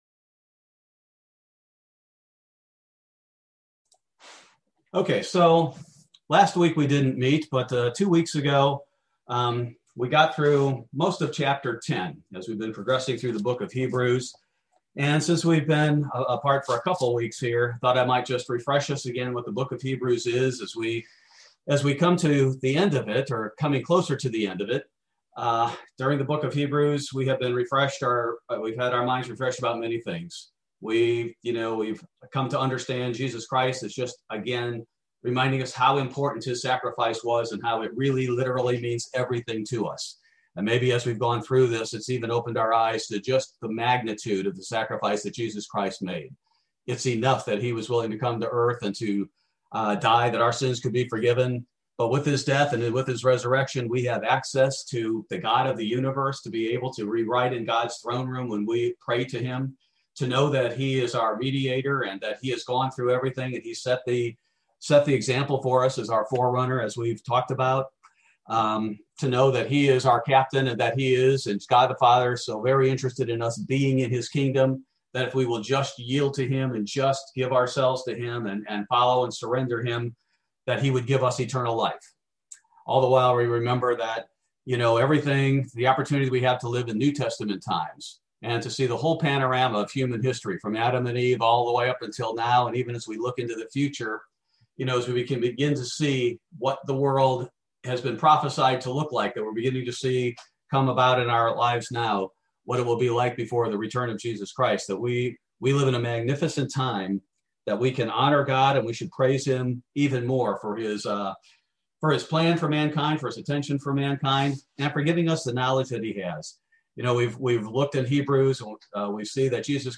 Bible Study - February 3, 2021